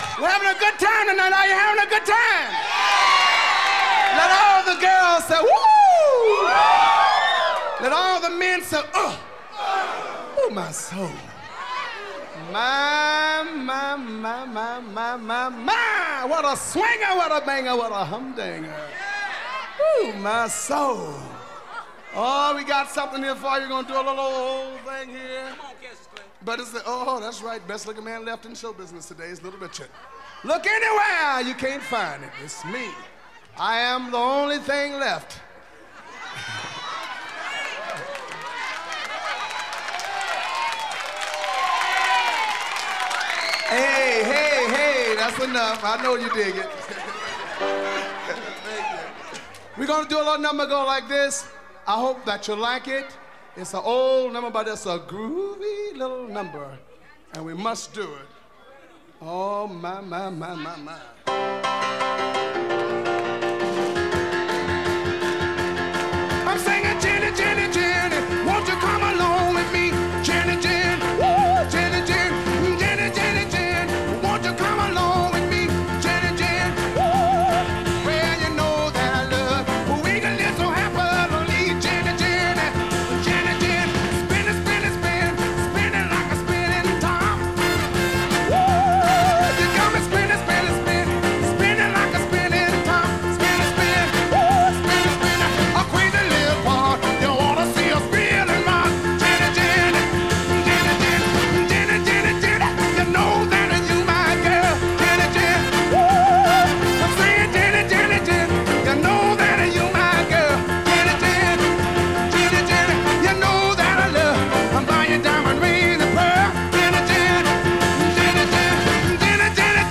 Жанр Рок-н-ролл, госпел